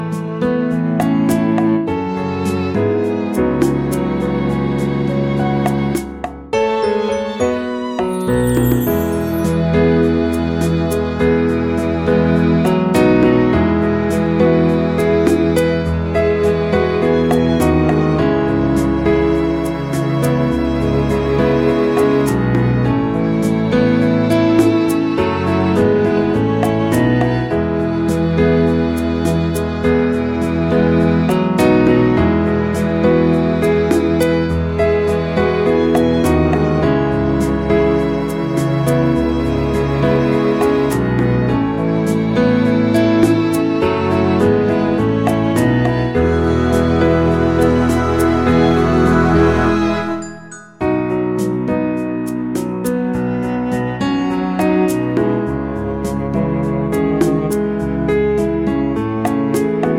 Up 2 Semitones For Female